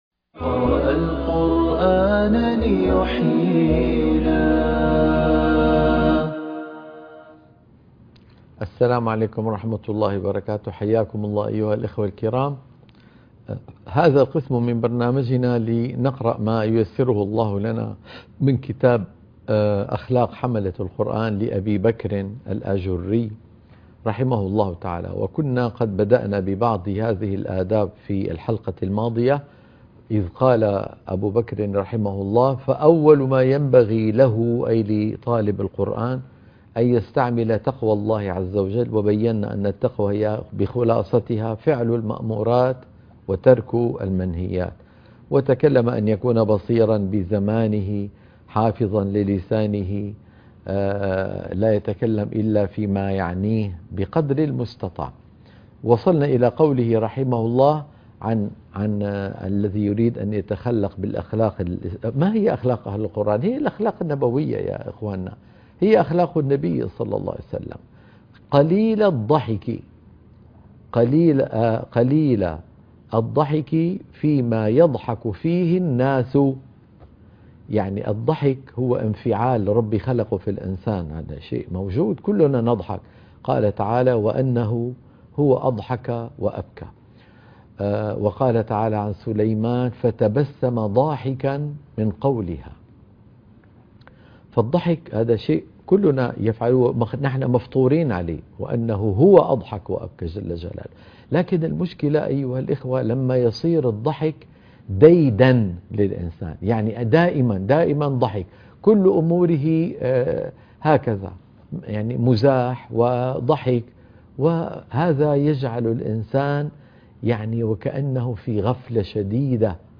قراءة كتاب أخلاق حملة القرآن